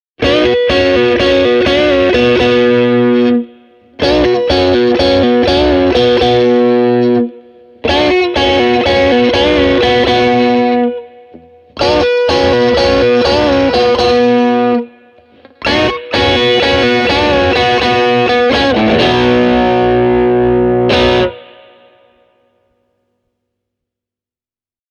Soundi on lämmin, muhkea ja täyteläinen, ja diskantissa on kaunis, samettisen kimmeltävä sävy.
Säröä saa Stratolla aikaiseksi ainoastaan boosterilla tai säröpedaalilla:
Stratocaster + säröpedaali (Boss SD-1)
stratocaster-e28093-overdrive-pedal.mp3